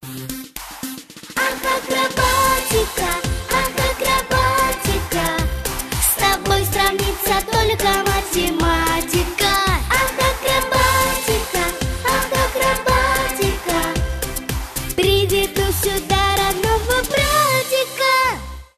детский голос , поп